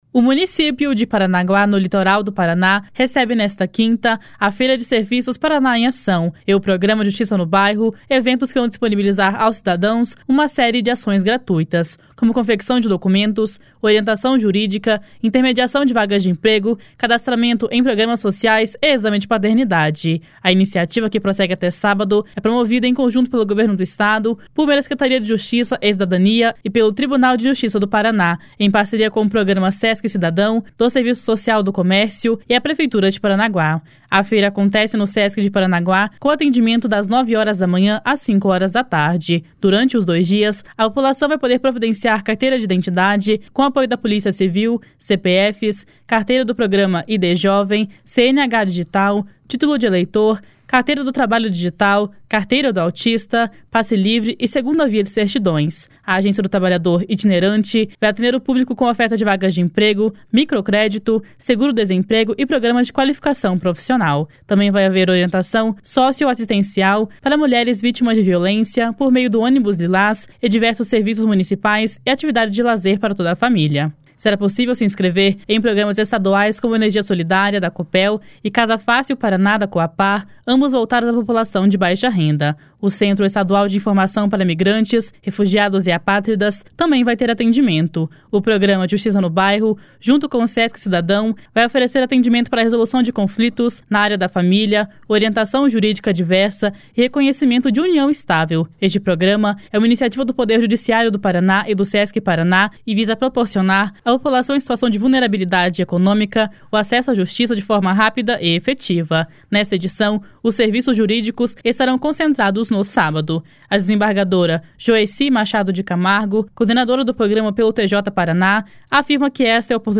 A desembargadora Joeci Machado de Camargo, coordenadora do programa pelo TJ-PR, afirma que esta é a oportunidade para quem precisa regularizar ou esclarecer questões jurídicas.
A secretária municipal de Assistência Social, Ana Paula Falanga, celebrou a série de serviços que serão realizados.